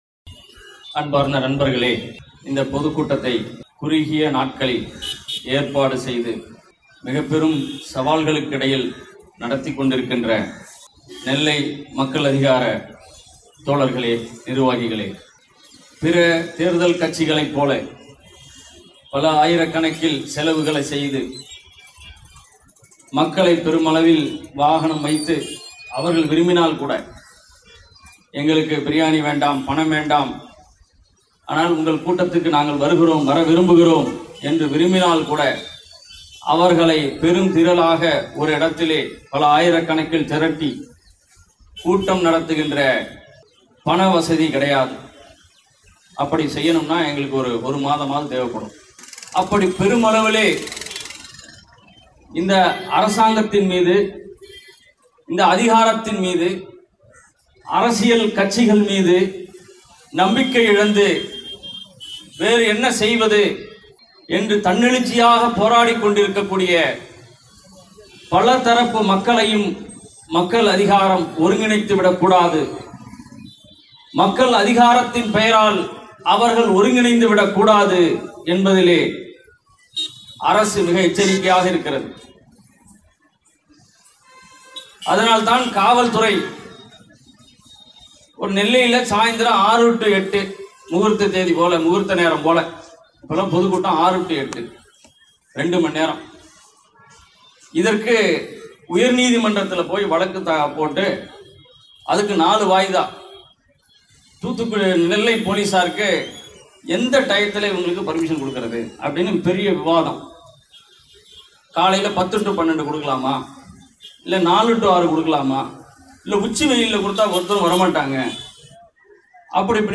கார்ப்பரேட் – காவி பாசிசம் – எதிர்த்து நில் ! என்ற திருச்சி மாநாட்டின் அறைகூவலையொட்டி நெல்லை மாவட்டத்தில் மக்கள் அதிகாரம் சார்பாக 11.04.19 அன்று பொதுக்கூட்டம் நடைபெற்றது.